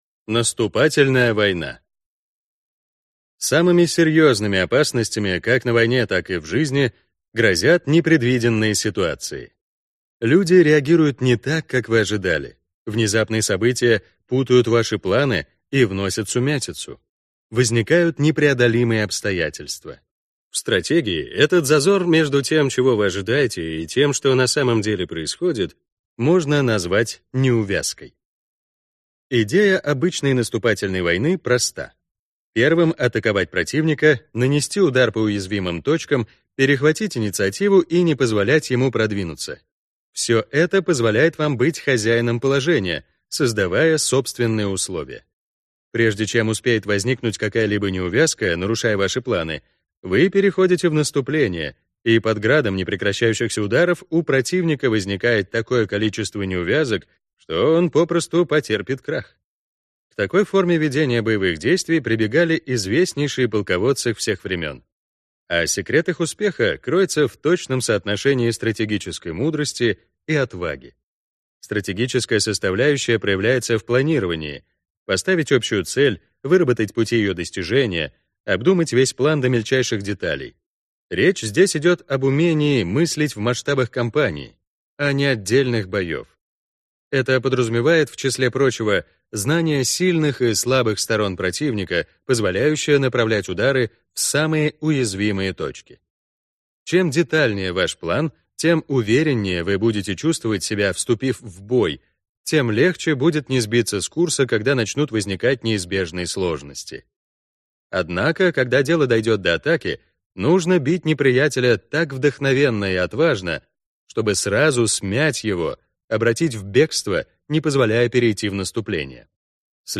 Аудиокнига 33 стратегии войны. Часть 4 | Библиотека аудиокниг